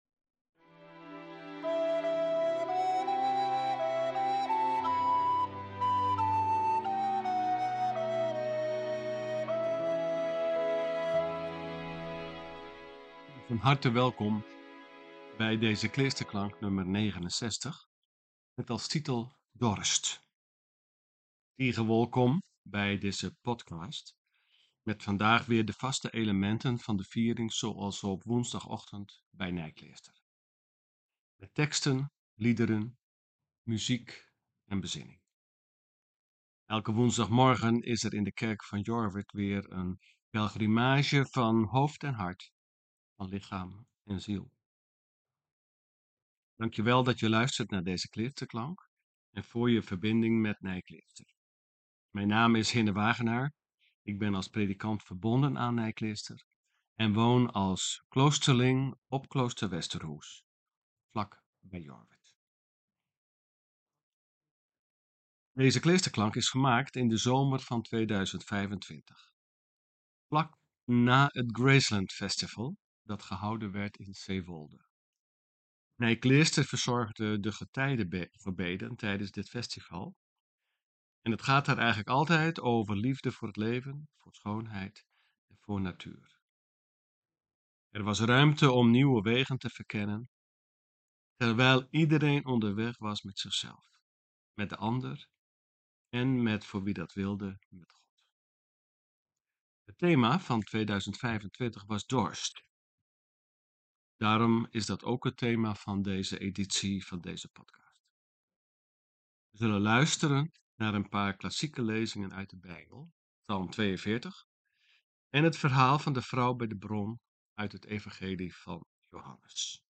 voor 8 stemmen